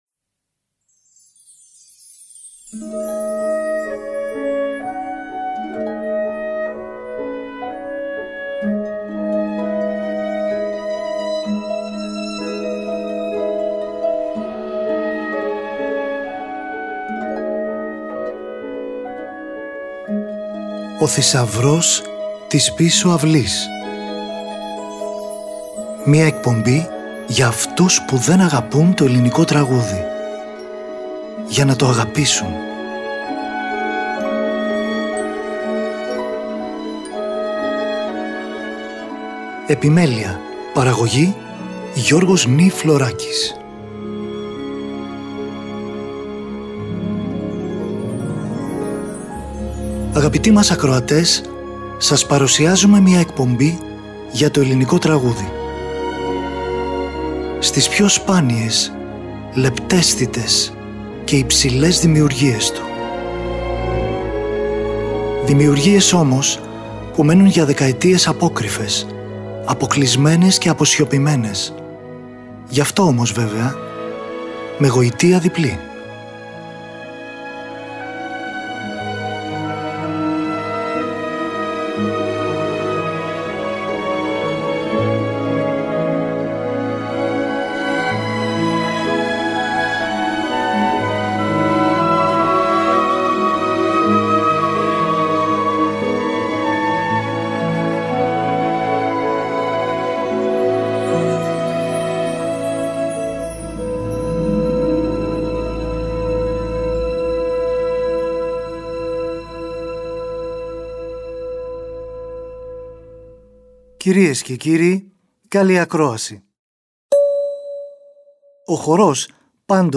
Μουσικη για Μπαλετο